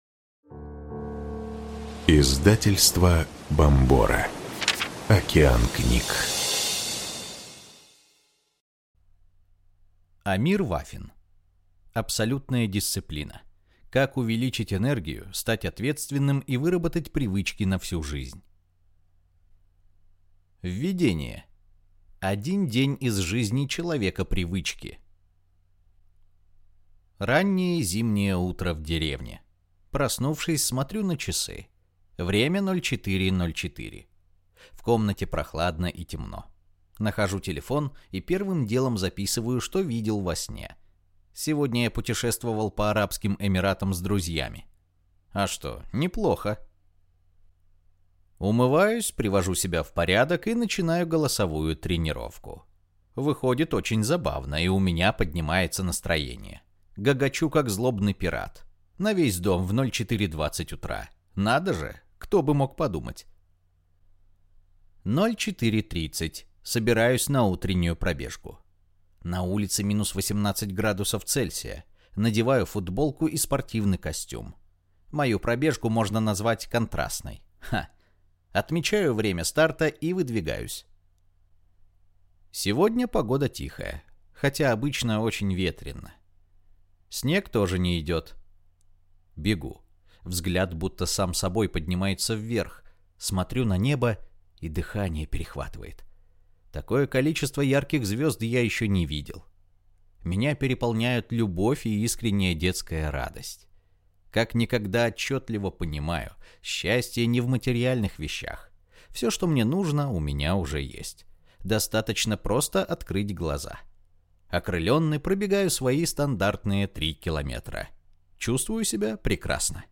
Аудиокнига Абсолютная дисциплина. Как увеличить энергию, стать ответственным и выработать привычки на всю жизнь | Библиотека аудиокниг